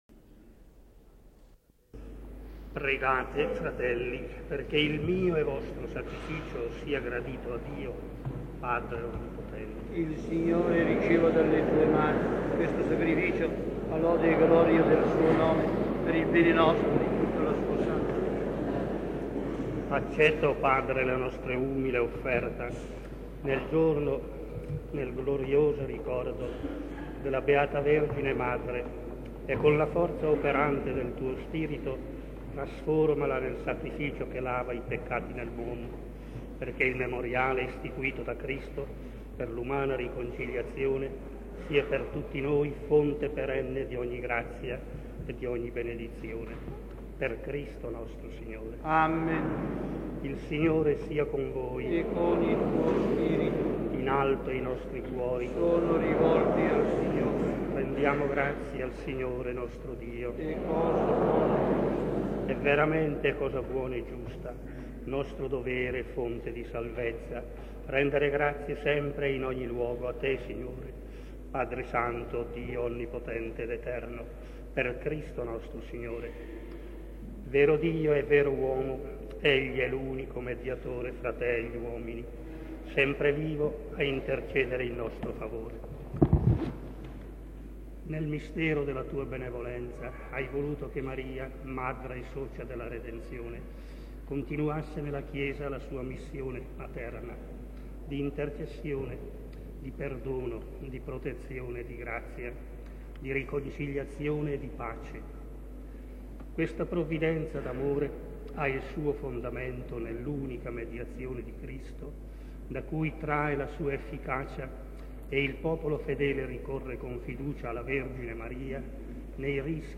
Messa Solenne
S. Alessandro in Colonna